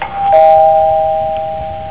doorbell
doorbell.au